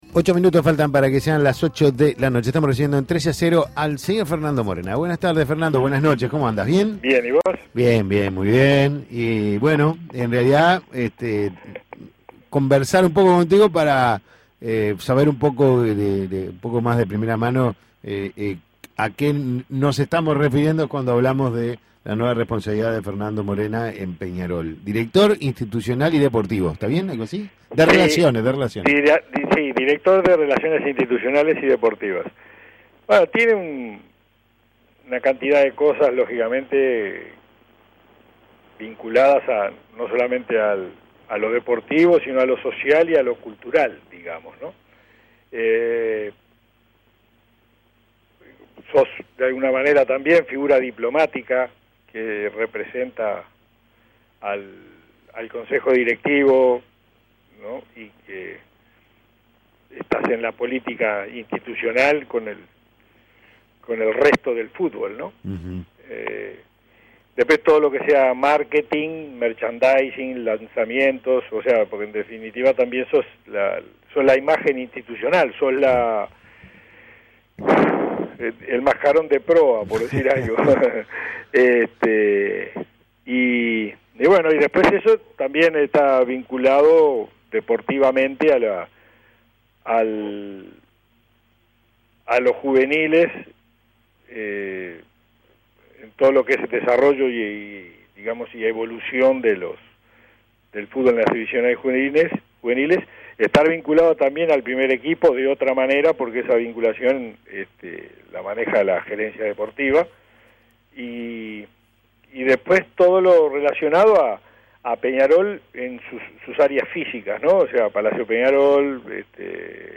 Testimonios Escuche la nota a Fernando Morena Imprimir A- A A+ Fernando Morena hablò sobre su cargo en Peñarol.